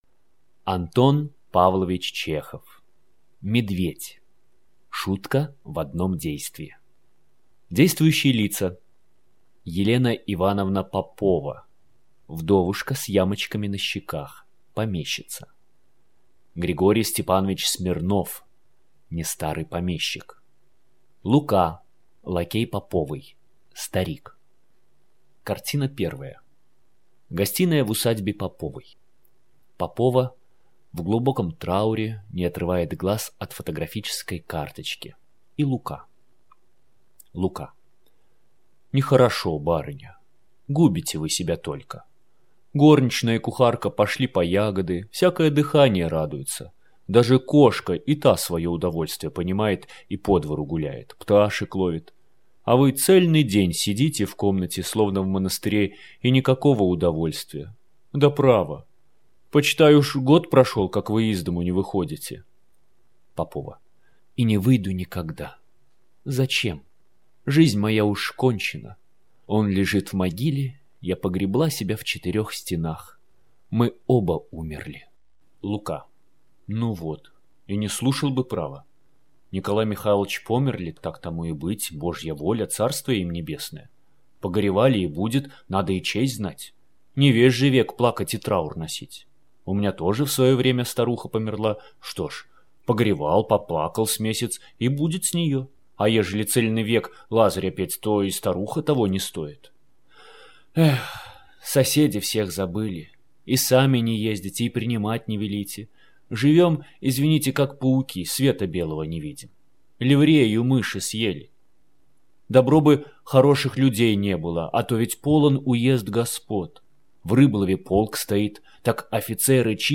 Аудиокнига Медведь | Библиотека аудиокниг